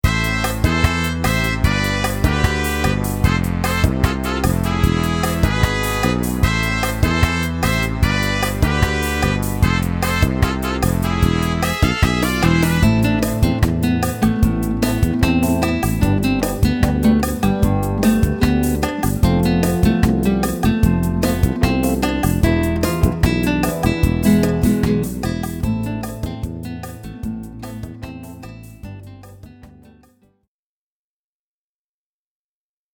• Thema: Süd-Amerikanisch
• Instrumenten: Gitarre Solo
• mit beispiel / Begleit CD mit Orchester